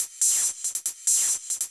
Index of /musicradar/ultimate-hihat-samples/140bpm
UHH_ElectroHatC_140-05.wav